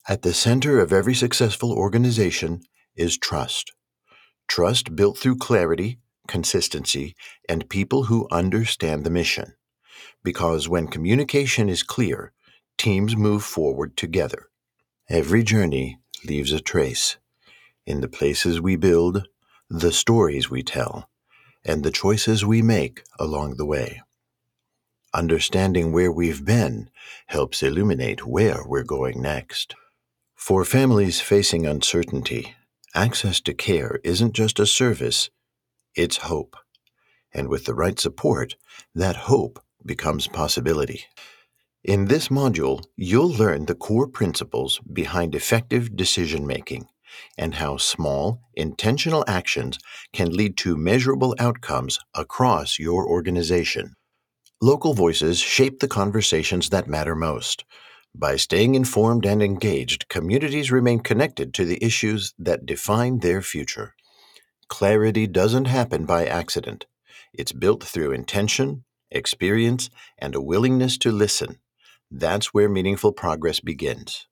Clear, confident narration for corporate, nonprofit, and public media projects.
Corporate & Institutional Narration Demo
A steady, experienced voice for projects where clarity and credibility matter.
Clear, natural delivery that supports the message without drawing attention to itself